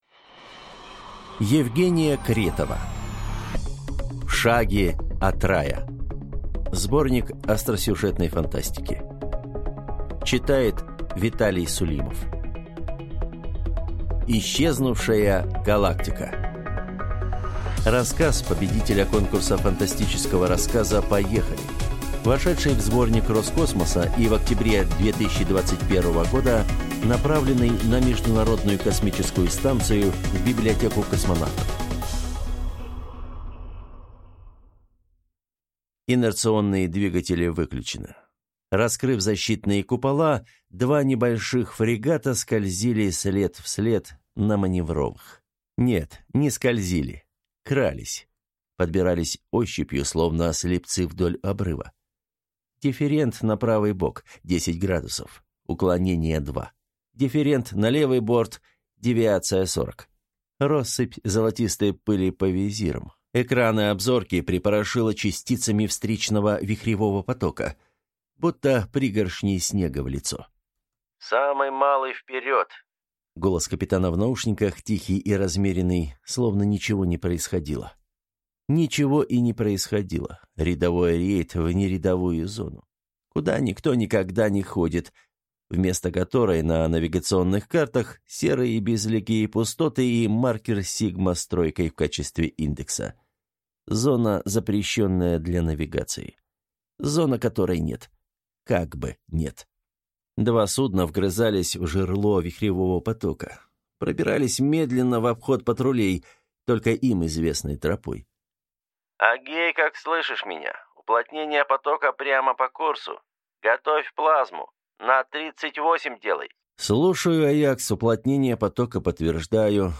Аудиокнига В шаге от Рая | Библиотека аудиокниг